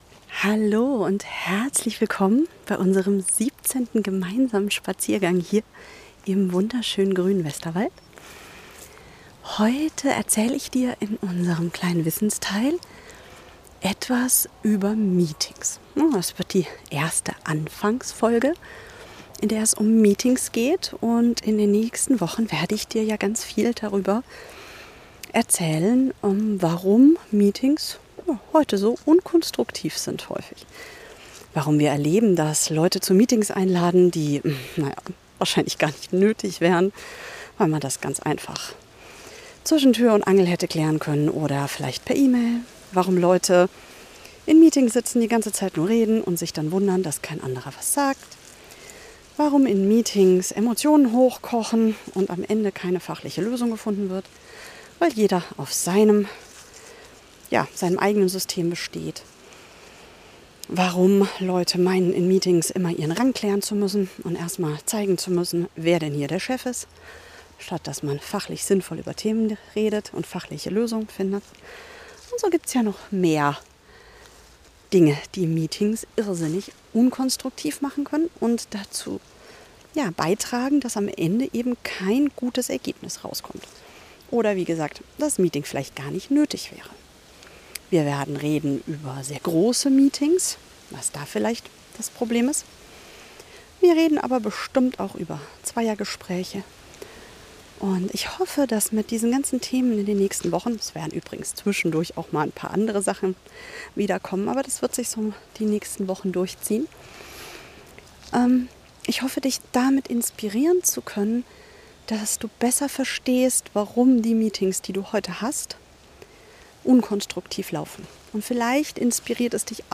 Heute laufe ich durch den Westerwald und erzähle dir etwas Faszinierendes über Meetings: Pauli findet einen viel zu großen Stock und ich finde heraus, dass wir seit 300.000 Jahren für Meetings gemacht sind.